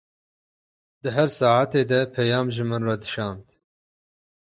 Lees meer Betekenis (Engels) message Vertalings message Bescheid Mandel messaggio ileti mesaj Uitgespreek as (IPA) /pɛˈjɑːm/ Etimologie (Engels) Compare Persian پیام (peyâm).